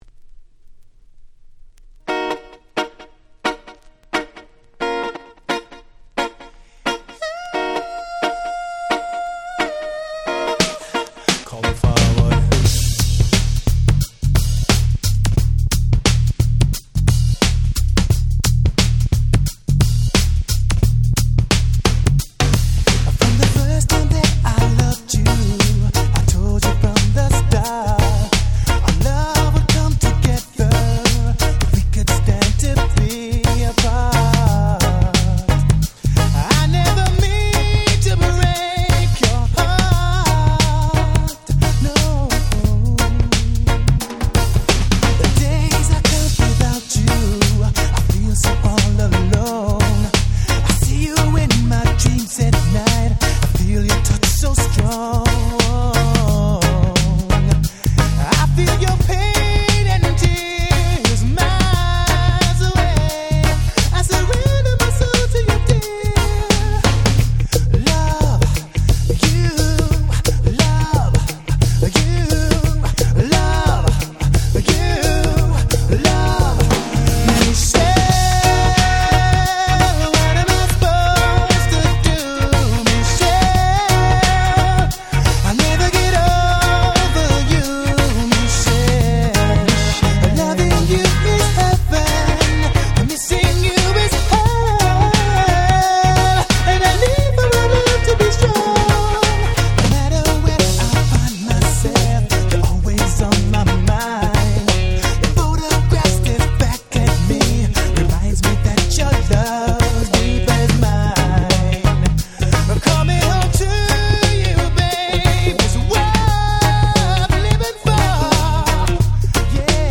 95' Smash Hit R&B !!
ほんのりReggae風味の歌声に切ないメロディー、当時地味にヒットした1枚。